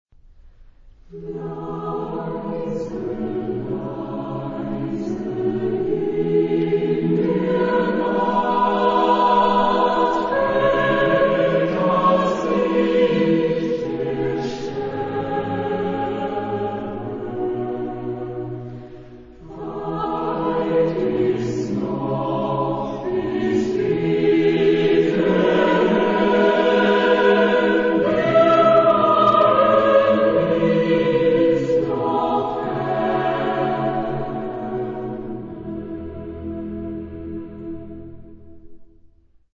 Genre-Stil-Form: geistlich ; Chor ; Choral
Charakter des Stückes: ruhig
Chorgattung: SATB  (4 gemischter Chor Stimmen )
Tonart(en): E-Dur